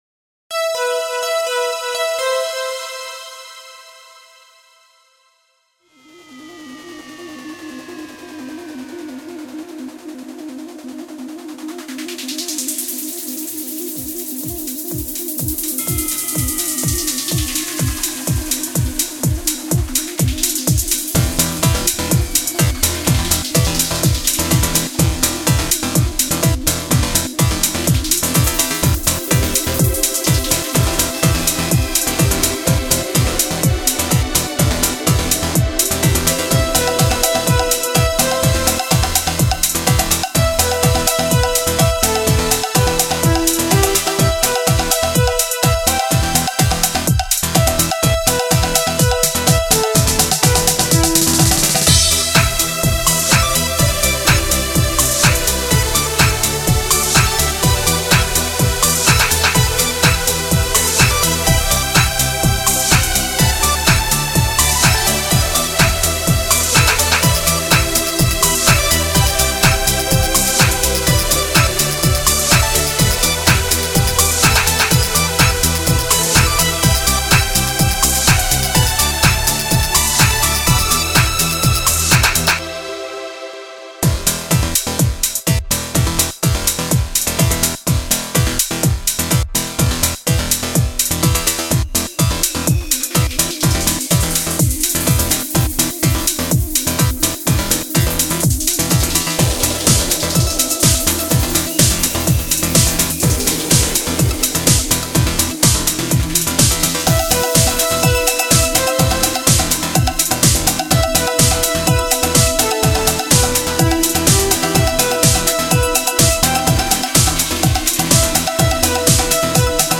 Ich mache hauptsächlich elektronische, instrumentale Musik.